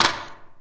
dd5de5f0c4 q3rally / baseq3r / sound / weapons / grenade / hgrenb2a.wav q3rally dcdc537bfa Added files 2011-02-18 14:31:32 +00:00 53 KiB Raw History